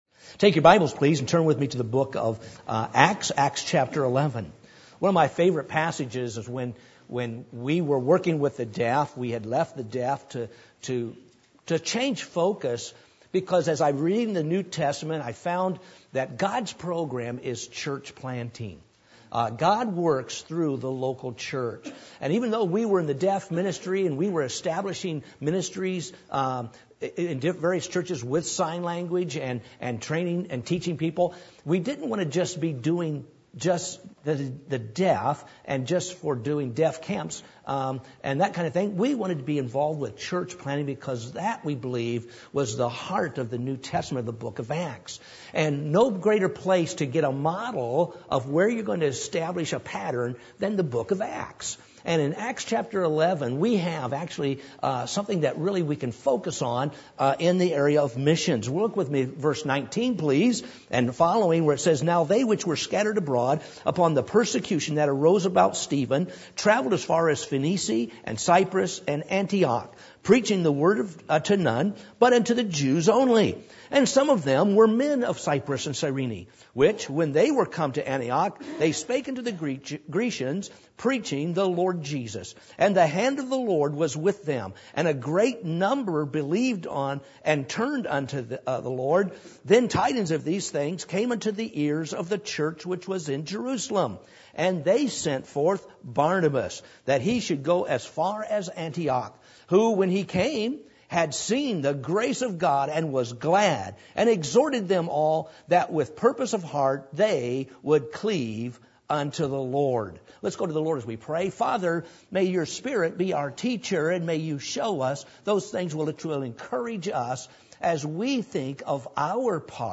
Passage: Acts 11:19-24 Service Type: Midweek Meeting